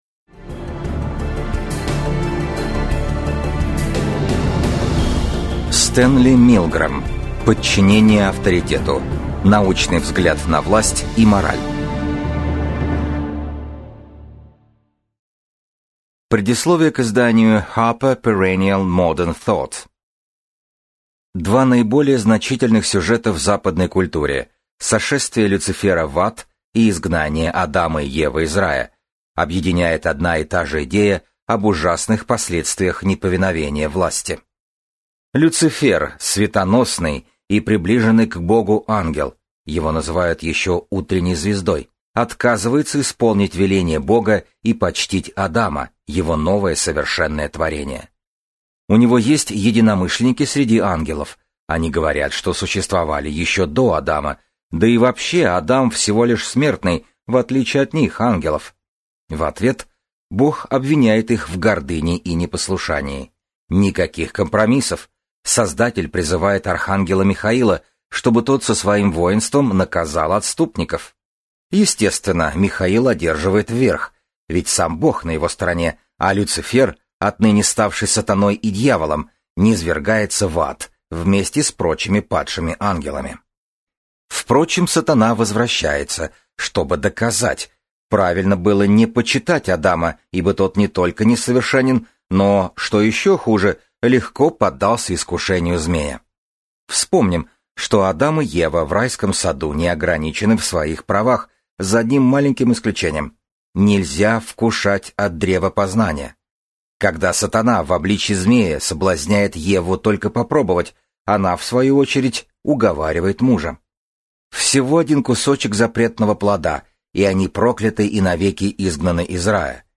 Аудиокнига Подчинение авторитету. Научный взгляд на власть и мораль | Библиотека аудиокниг